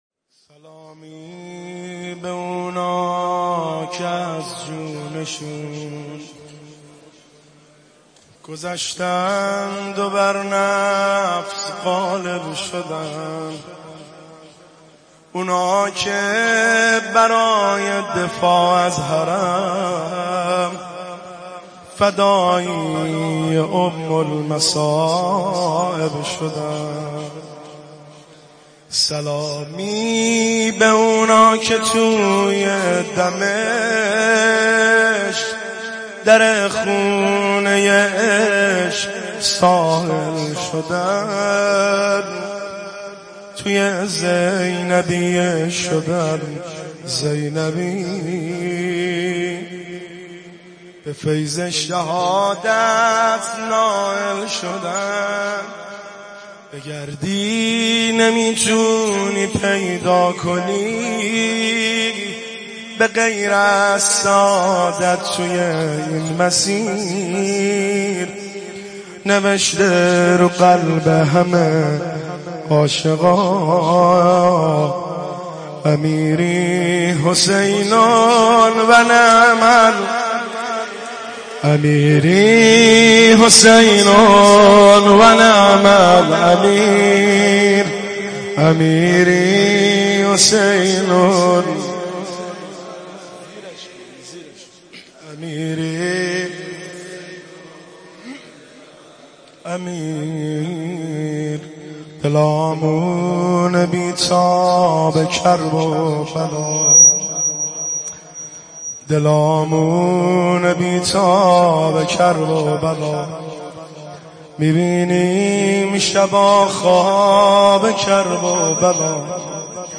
روضه شب چهارم محرم